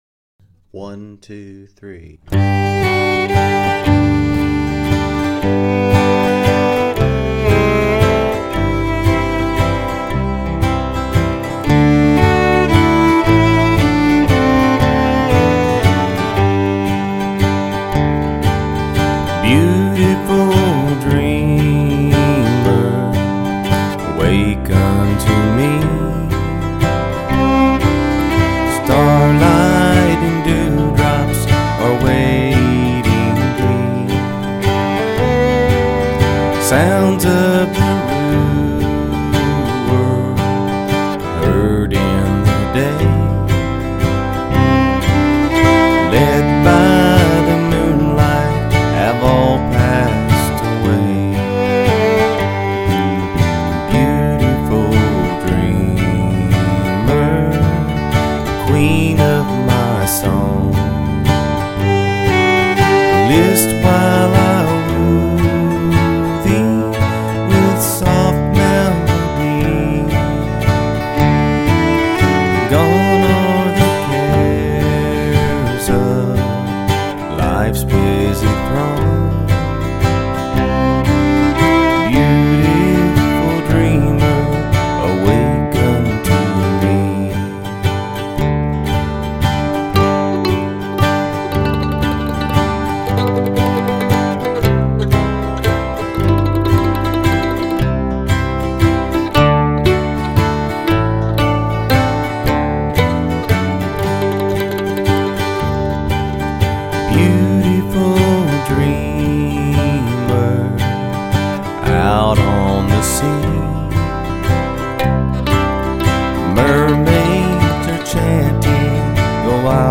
Beautiful-Dreamer-Key-G.mp3